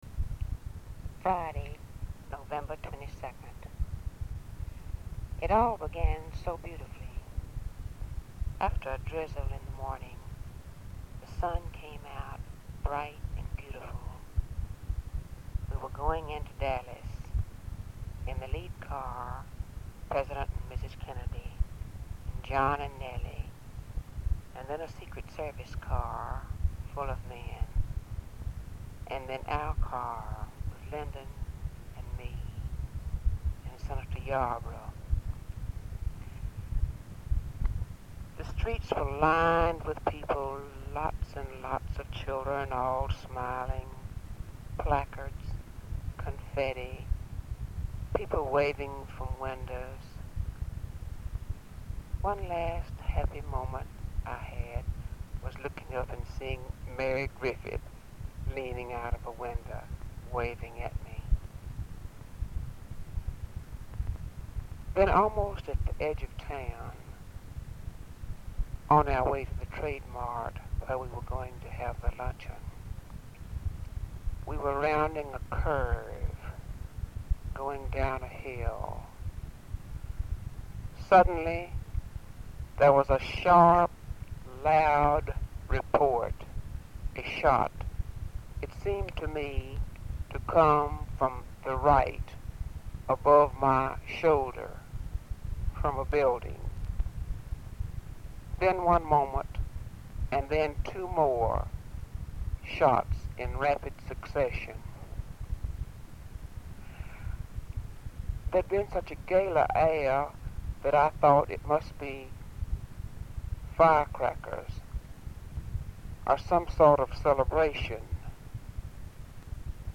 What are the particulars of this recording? Format Audio tape